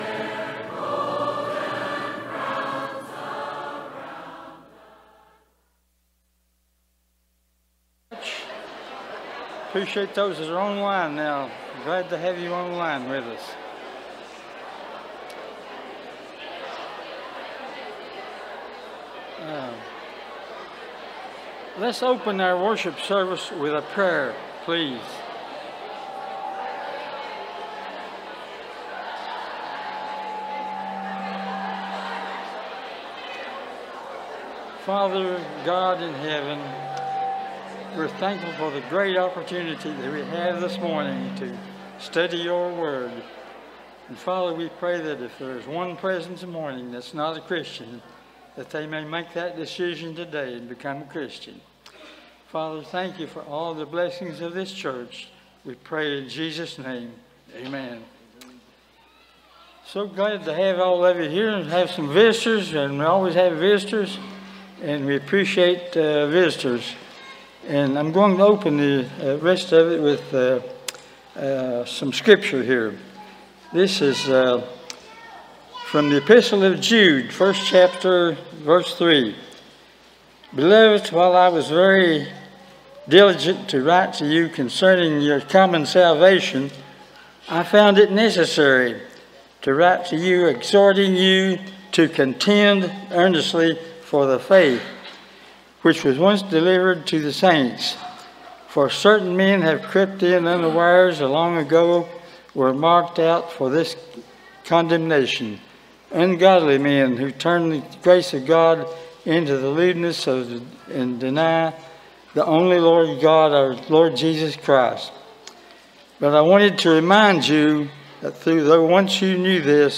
Proverbs 24:1, English Standard Version Series: Sunday AM Service